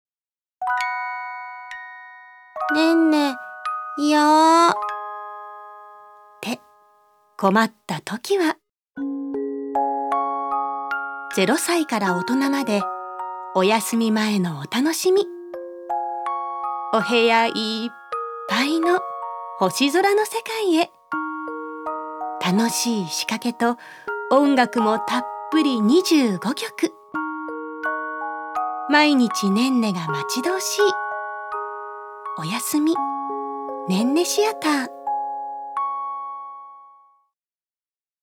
女性タレント
ナレーション５